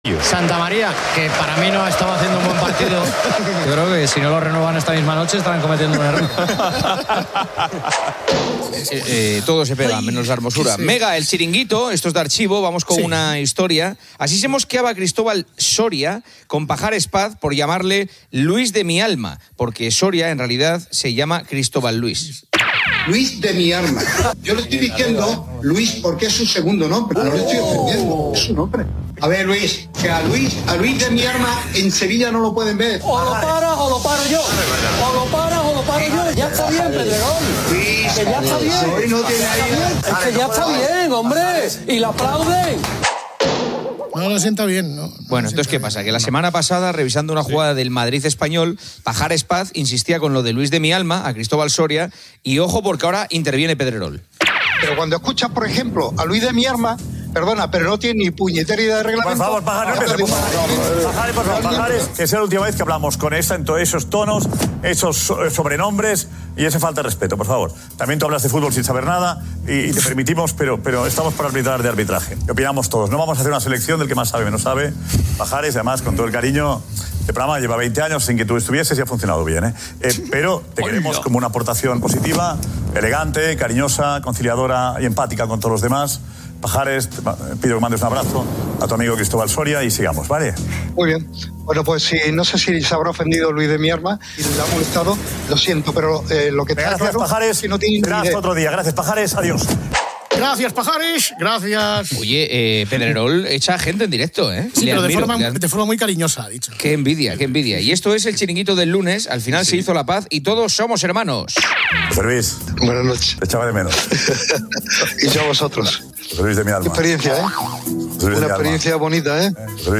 También se critica el rendimiento del FC Barcelona y las disculpas recurrentes. Antonio Lobato y Pepe Bordalás discuten el valor de la plantilla del Barça.